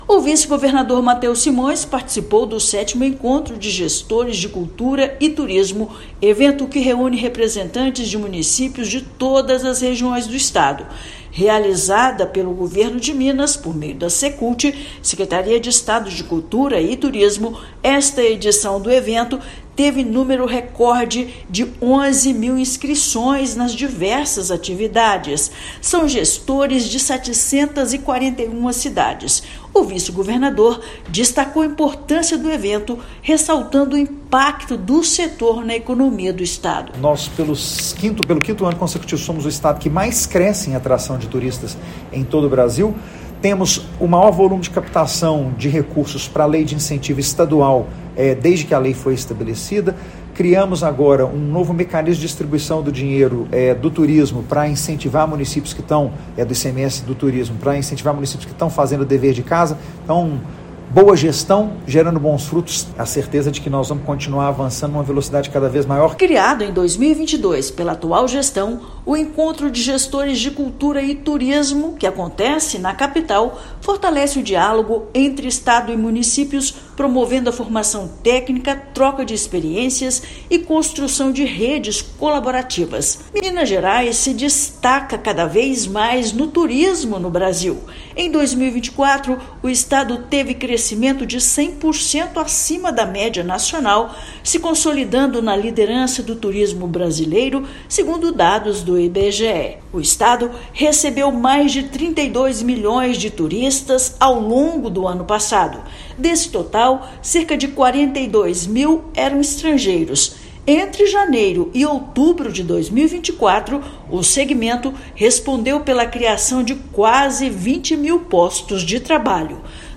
Com participação do vice-governador, sétima edição do fórum de articulação registra mais de 11 mil inscrições e participação de dirigentes de 741 cidades mineiras. Ouça matéria de rádio.